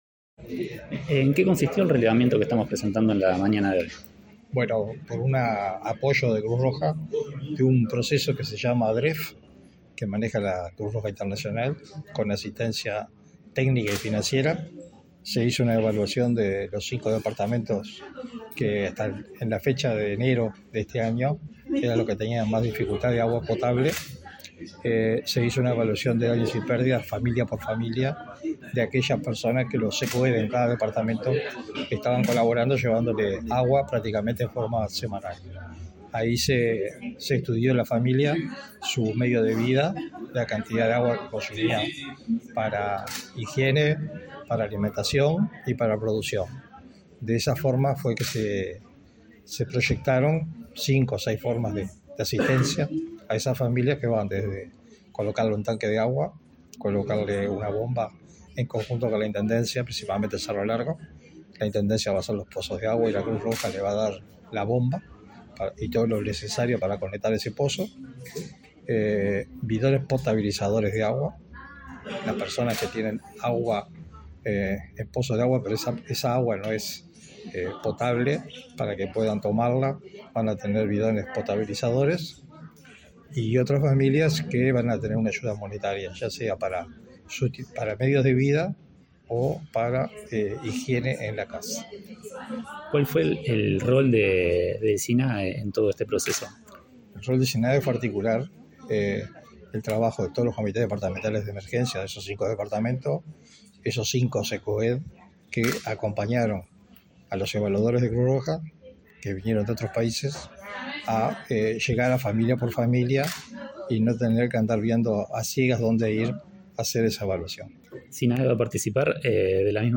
Entrevista al director del Sinae, Sergio Rico